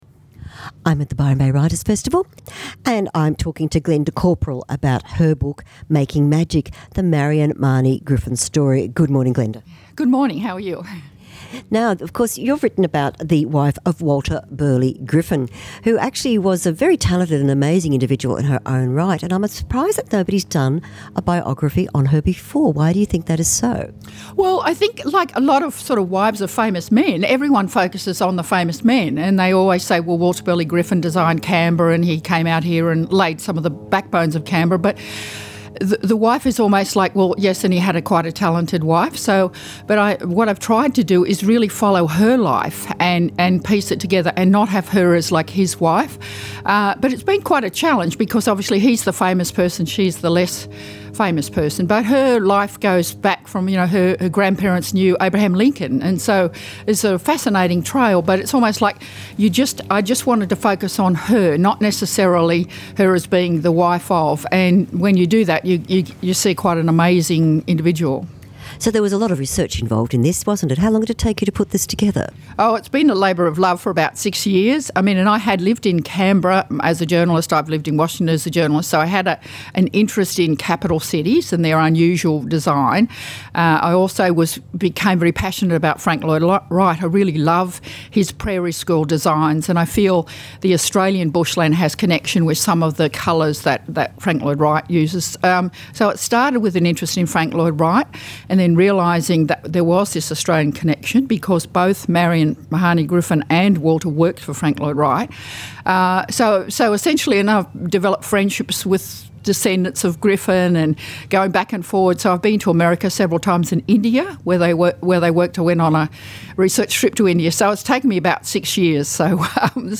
Books and Authors - live interviews
Recorded at Byron  Writers Festival 2015